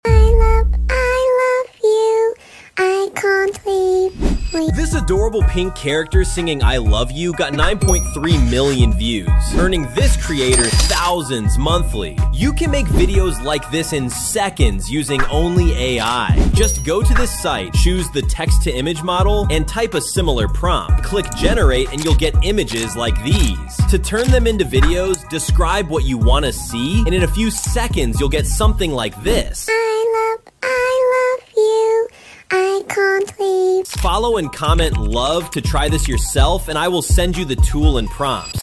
This adorable pink character singing sound effects free download
This adorable pink character singing "I love you" hit 9.3 MILLION views 💕 And creators are making THOUSANDS monthly with wholesome AI content like this 💰 I recreated this heartwarming viral moment in under 10 minutes using free AI tools...